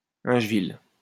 Aingeville (French pronunciation: [ɛ̃ʒvil]